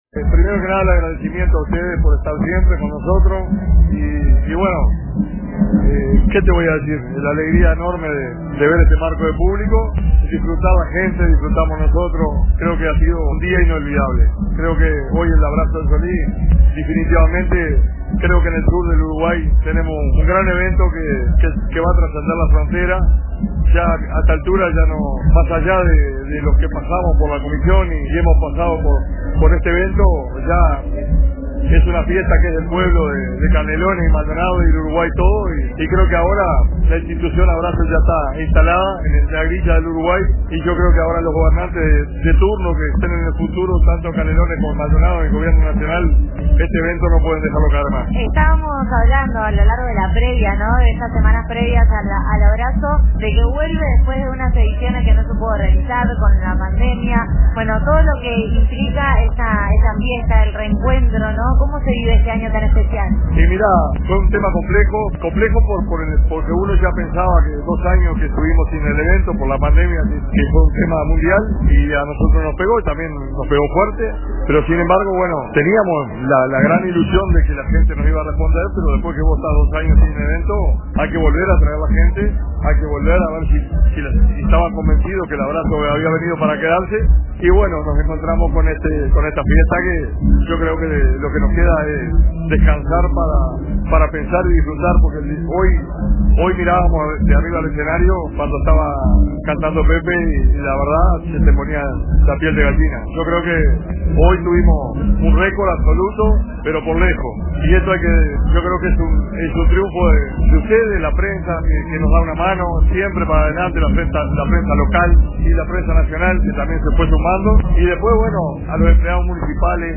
en la charla